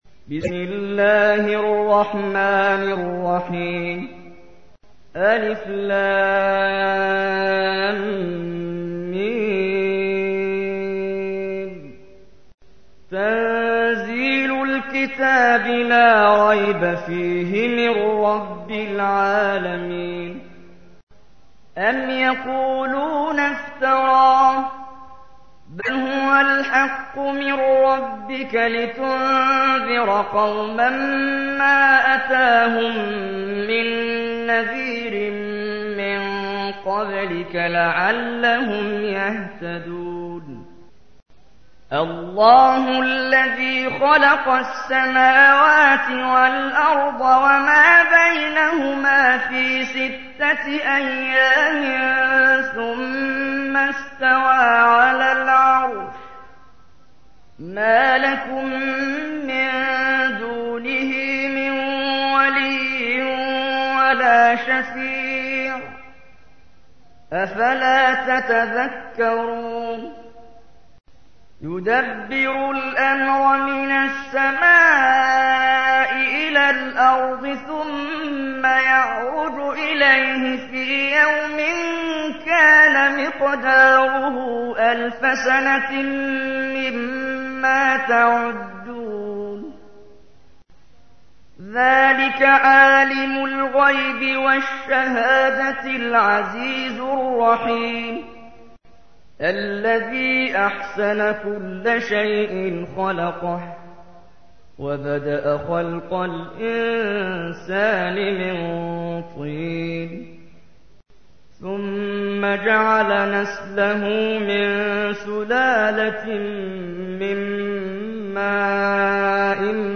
تحميل : 32. سورة السجدة / القارئ محمد جبريل / القرآن الكريم / موقع يا حسين